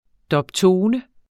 Udtale [ dʌbˈtoːnə ]